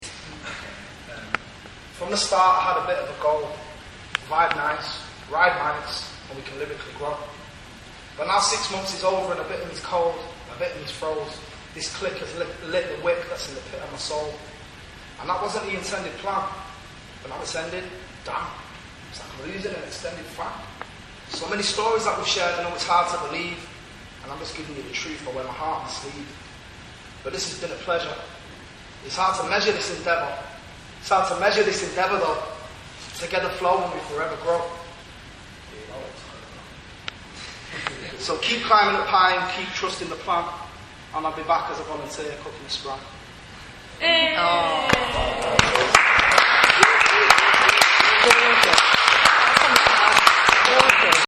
The project focused on musical performance, making use of rap, hip hop as well as spoken word poetry.